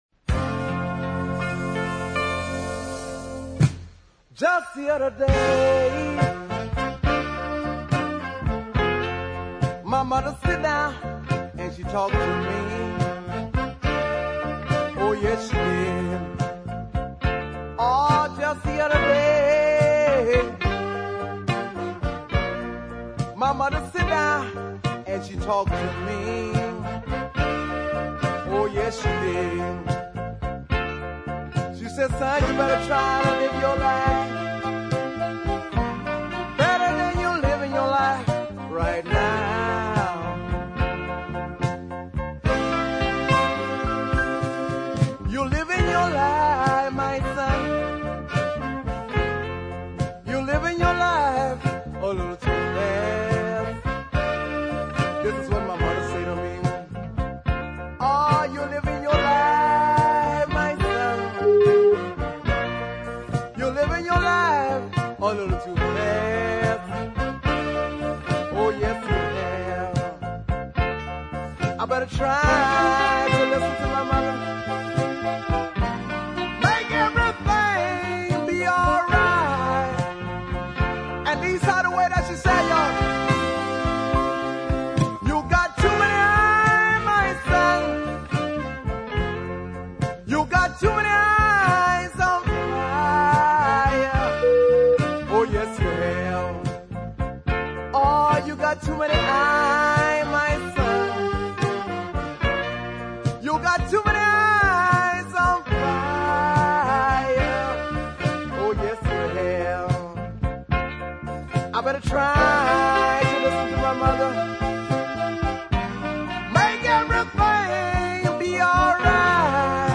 Southern Soul and zydeco.
swamp pop ballad of no little charm
pumping piano over an excellent horn section